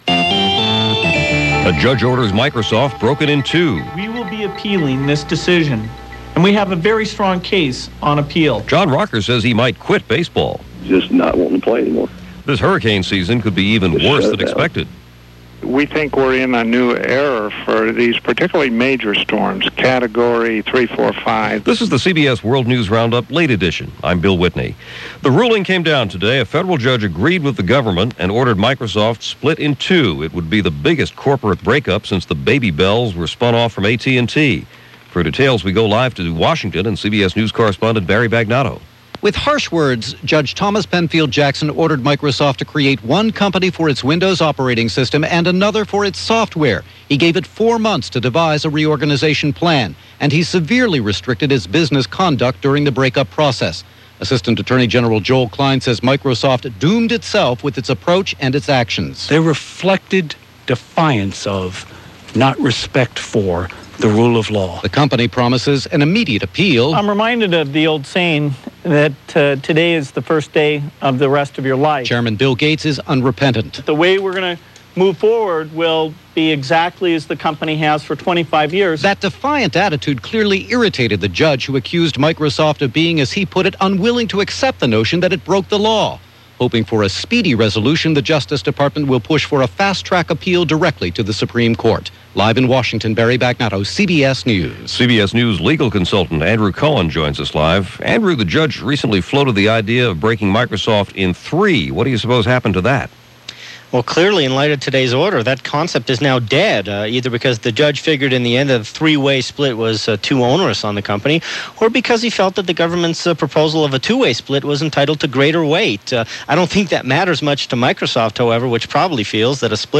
And that’s just a small slice of what went on, this June 6, 2000 as reported by The CBS World News Roundup: Late Edition.